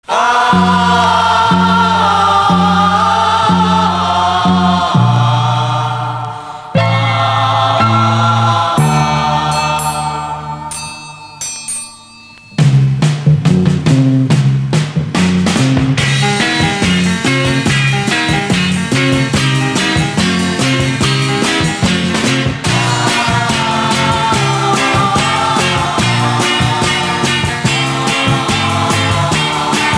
Pop psychédélique